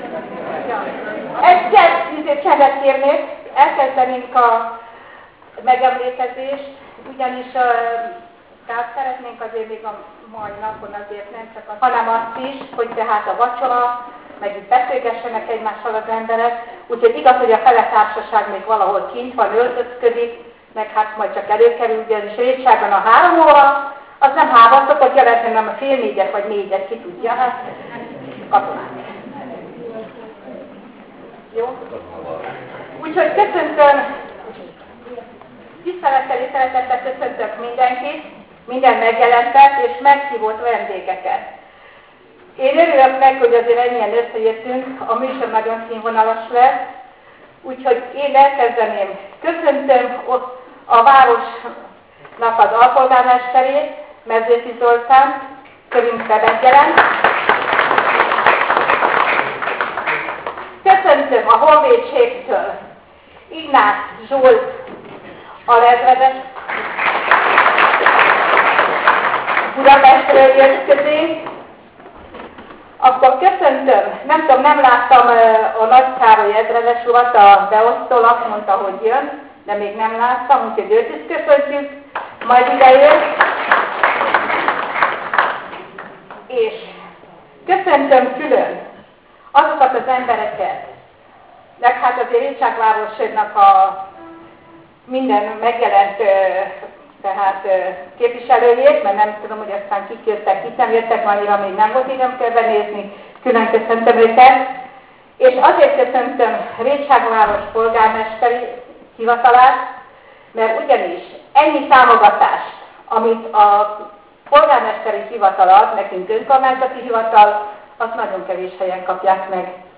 Ezen �vfordul� alkalm�b�l rendeztek d�sz�nneps�ggel kib�v�tett klubnapot a Hunyadi J�nos Nyug�llom�ny�ak Klubja vezet�i, szervez�i. M�skor az aul�t n�pes�tik be a klubtagok, most ezek mellett a sz�nh�ztermet t�lt�tt�k meg.